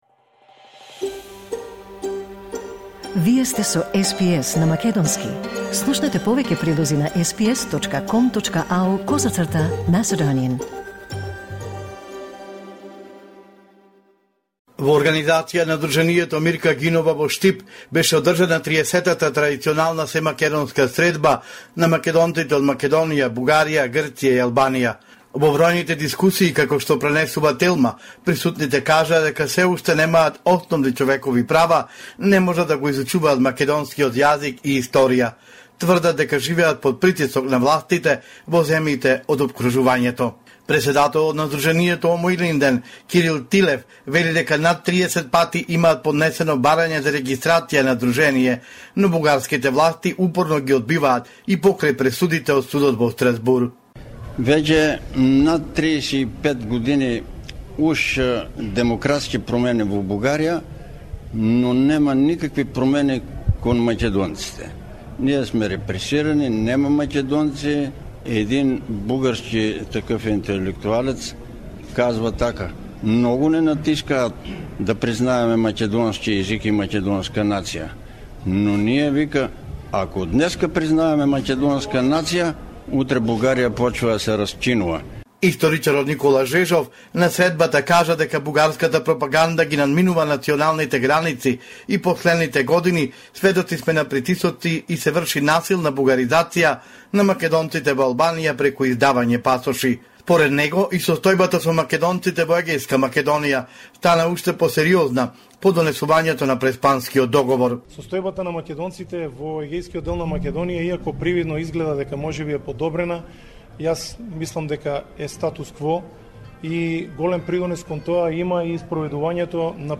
Извештај од Македонија 10 ноември 2025